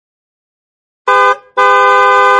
Categories: Sound Effect